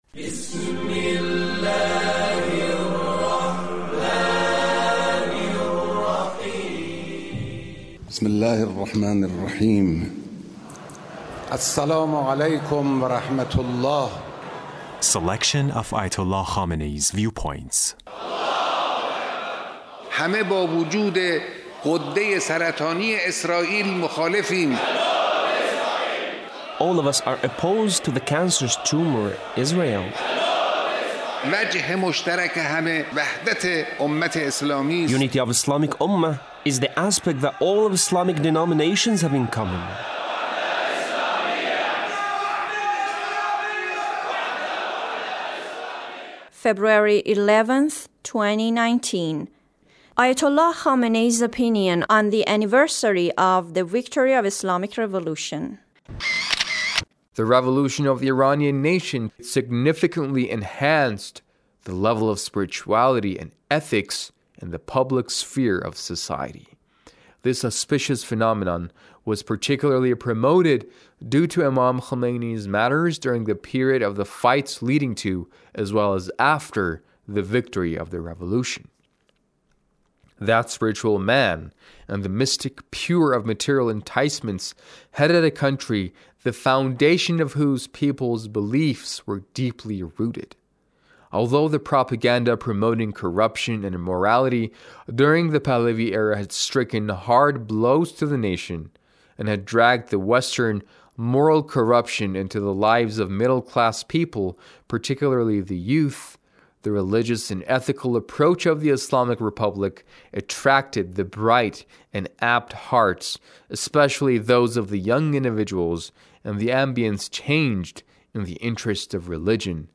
The Leader's speech on THE ANNIVERSARY OF THE ISLAMIC REVOLUTION-THE ACHIEVEMENTS OF THE REVOLUTION